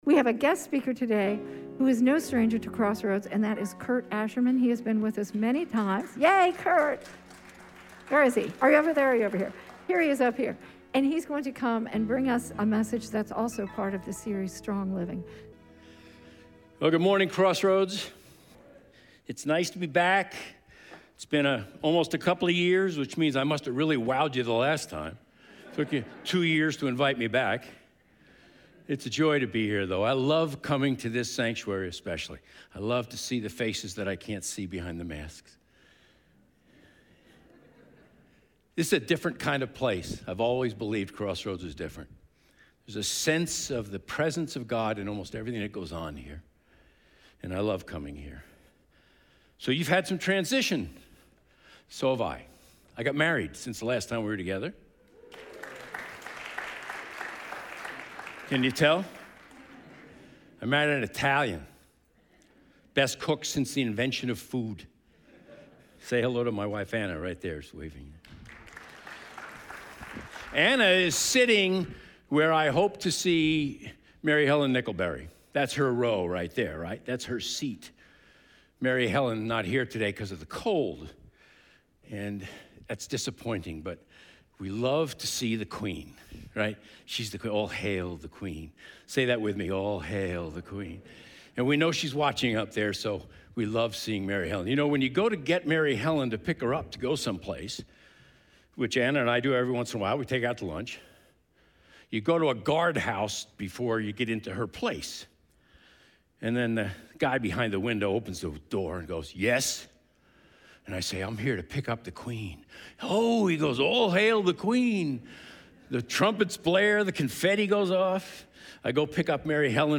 Sunday morning message Strong Living
Sermons